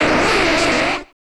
Pokemon X and Pokemon Y Cry Variations: